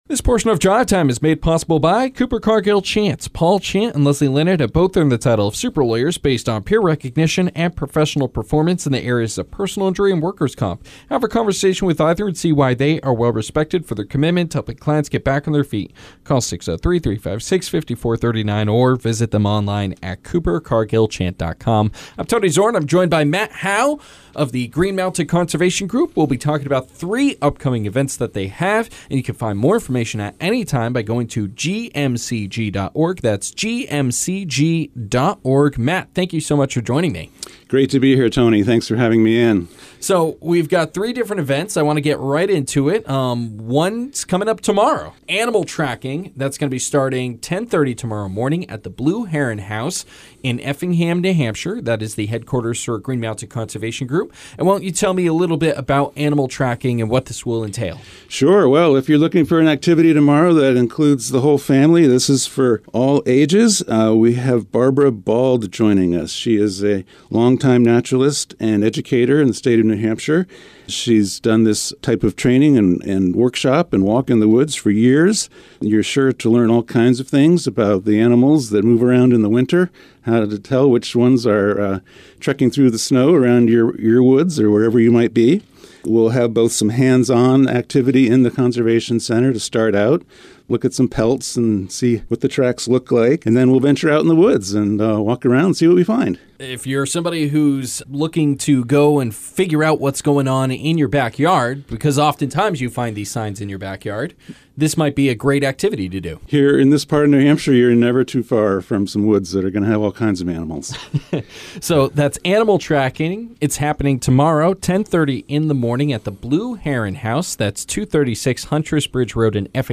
Drive Time Interview: Green Mountain Conservation Group
Drive Time Interviews are a specialty program on week days at 5pm where local not for profit organizations get a chance to talk about an upcoming event on air.